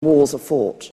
In England, this frequently weakens to no more than a little ə, just like the indefinite article a. (Other accents, including General American, can weaken are without losing its r sound.) Here are native utterances by British speakers in which are is merely a schwa: